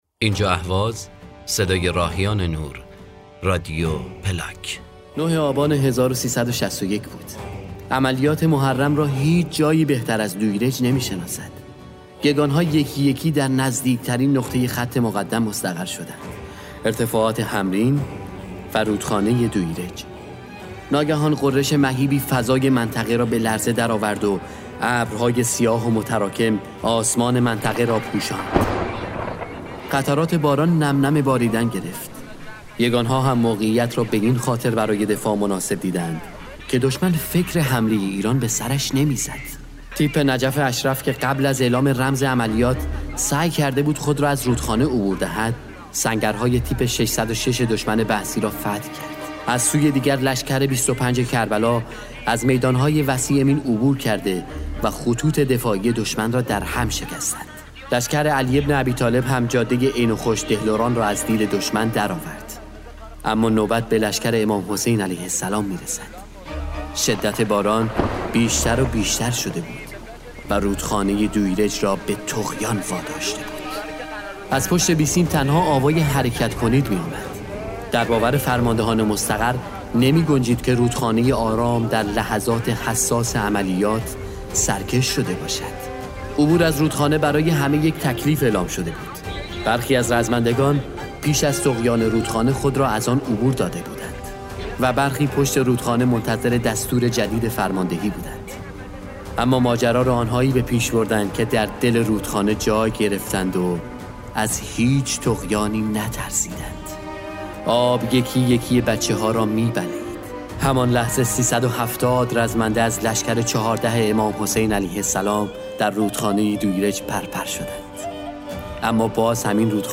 گلف چند رسانه‌ای صوت روایتگری شرهانی؛نردبانی به سوی آسمان شرهانی؛نردبانی به سوی آسمان مرورگر شما از Player پشتیبانی نمی‌کند.